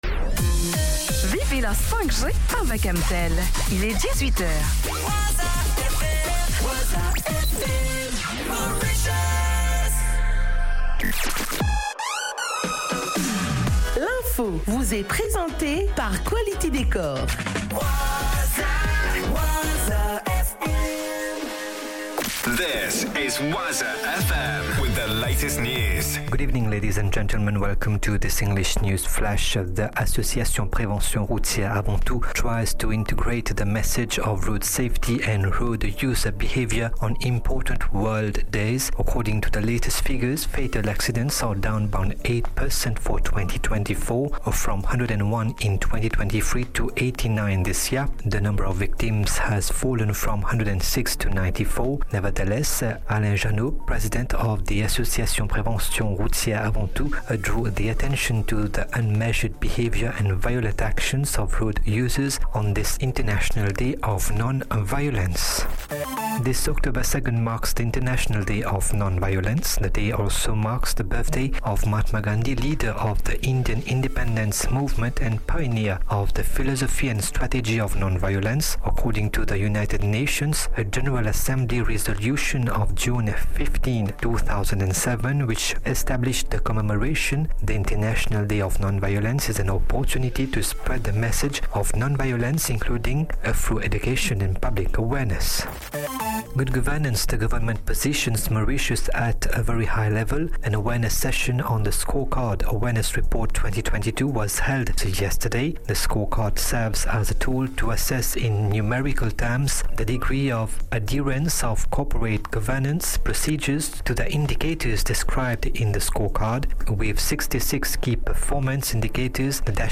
NEWS 18HR - 02 10 24